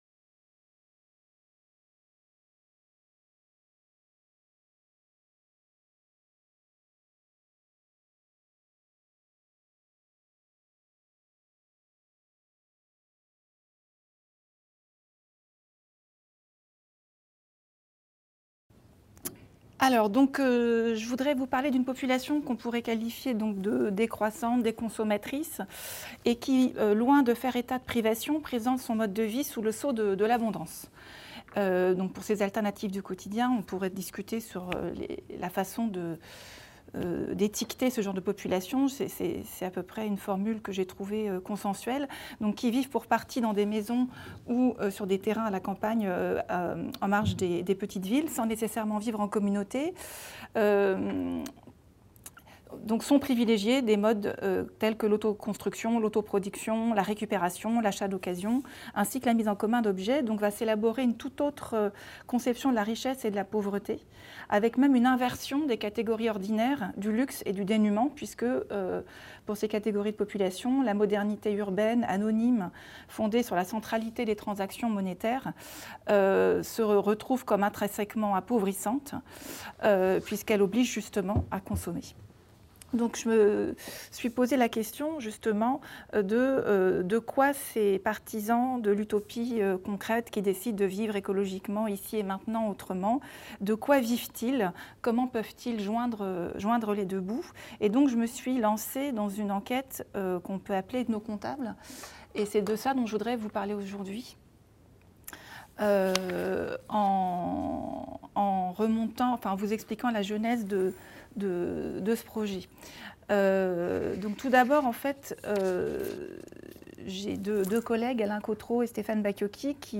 Séminaire du Master de sociologie